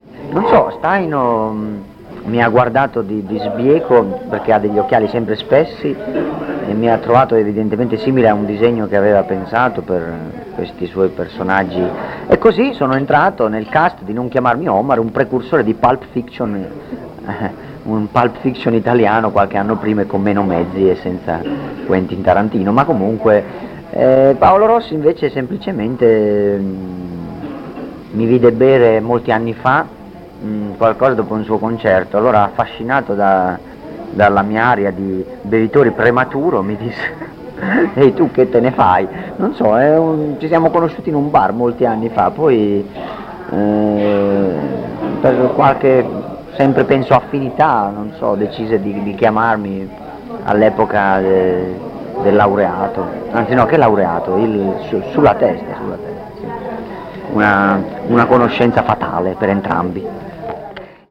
Dichiarazioni raccolte in occasione del concerto di Moncalvo (26 marzo 1996), quando l’organizzazione della serata di Ricaldone era ai suoi inizi.